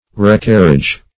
Recarriage \Re*car"riage\ (r[-e]*k[a^]r"r[i^]j), n. Act of carrying back.